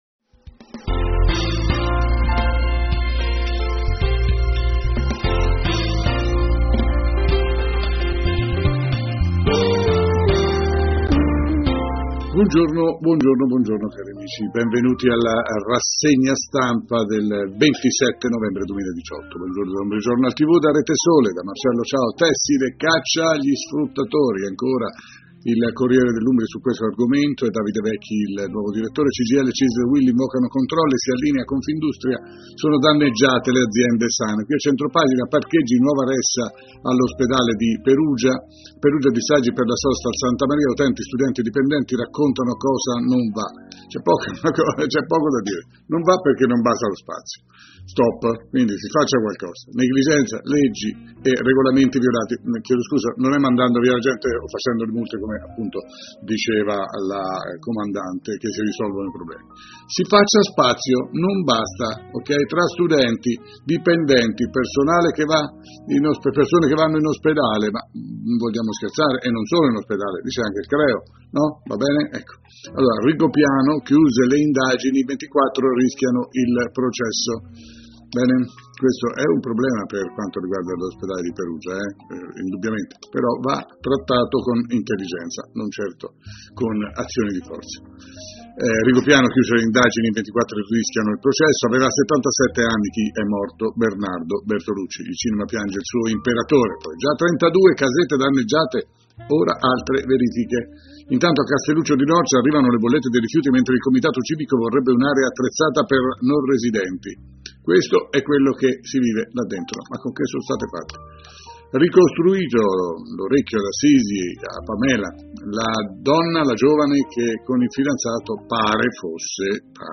LA-RASSEGNA-STAMPA_01-1.mp3